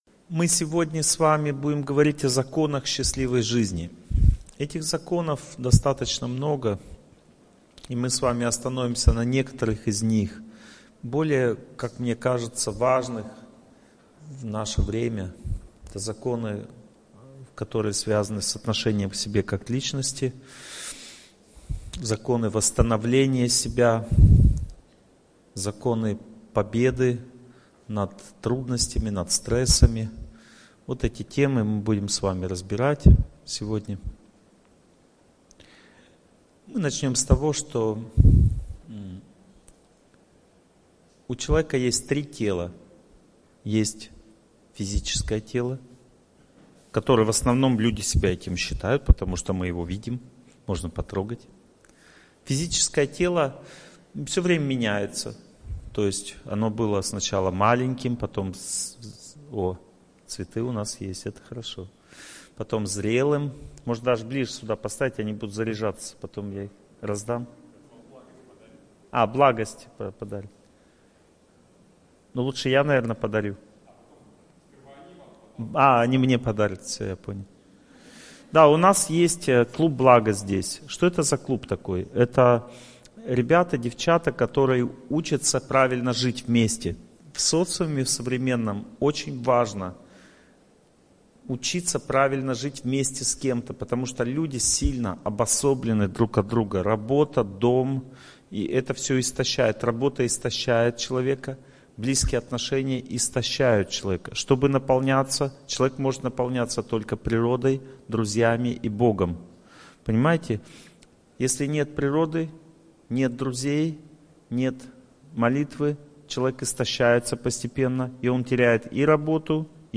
Аудиокнига Законы счастливой жизни. Глава 1 | Библиотека аудиокниг